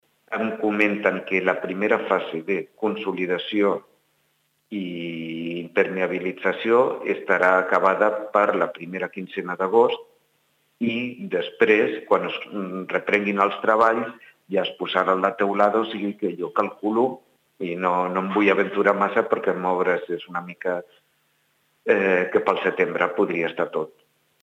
Rafa Delgado, regidor responsable del projecte de rehabilitació de Roca Rossa, ens explica els detalls